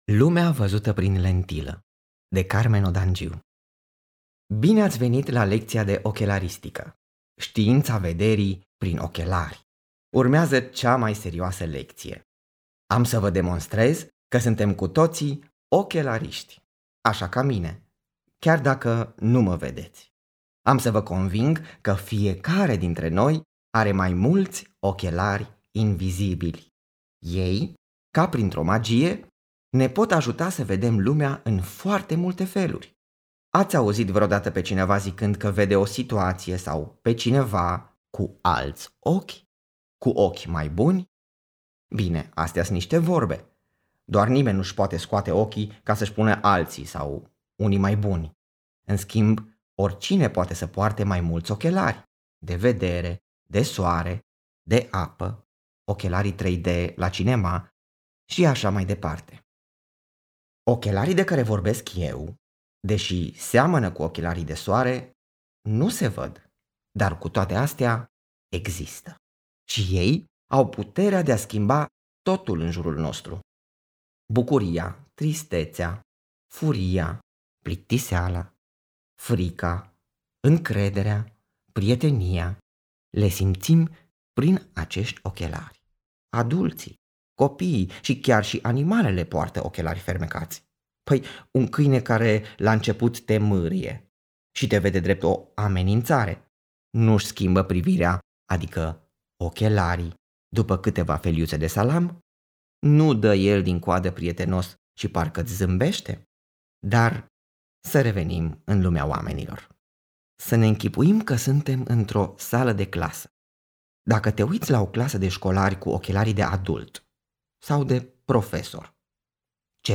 Spectacolele sunt, în fapt, povești terapeutice scrise cu ajutorul unui dramaturg și a unui expert în educație iar apoi interpretate de actori profesioniști. Poveștile abordează situații concrete, în care puterea de adaptare a copiilor este pusă la încercare.